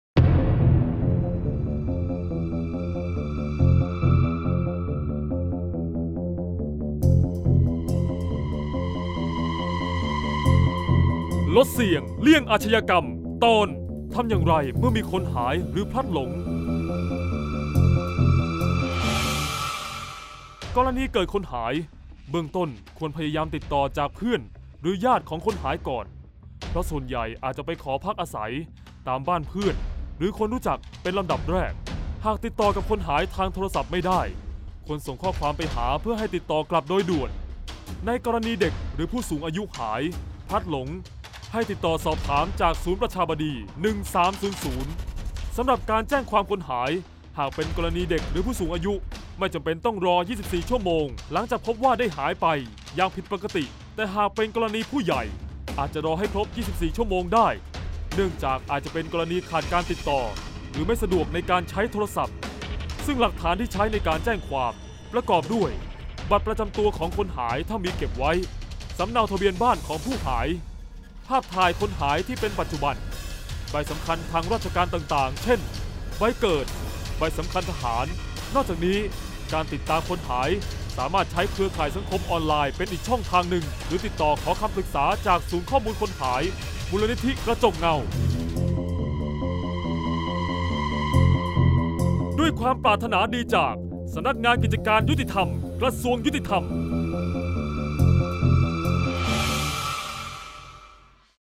เสียงบรรยาย ลดเสี่ยงเลี่ยงอาชญากรรม 35-ทำอย่างไรเมื่อมีคนหาย